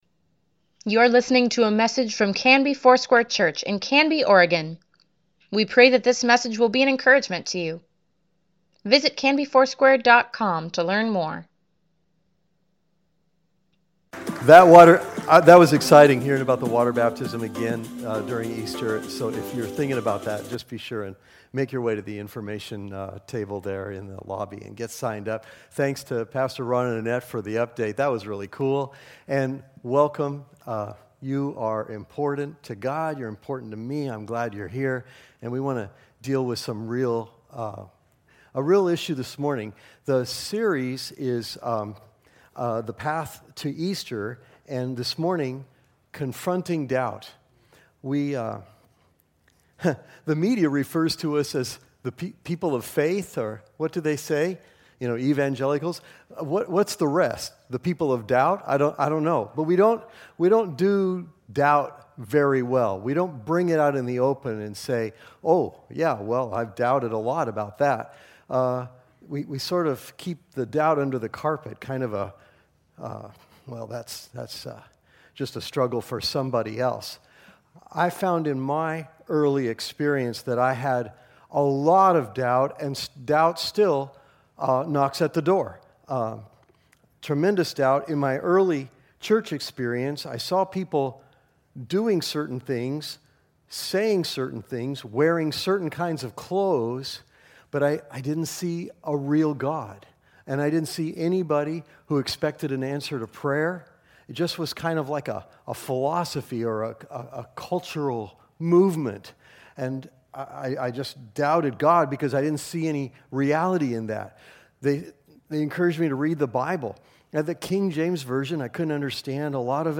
Weekly Email Water Baptism Prayer Events Sermons Give Care for Carus The Path to Easter (2) March 18, 2018